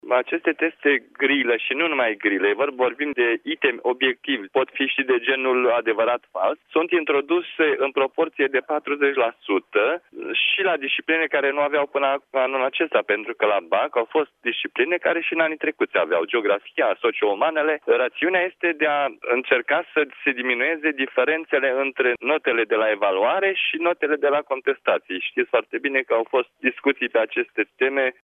Rostul acestei modificări este încercarea de a elimina cât mai mult posibil din subiectivismul corectorilor atunci când punctează lucrările elevilor, declară la Europa FM, președintele Centrului Naţional de Evaluare şi Examinare, Marian Șuță.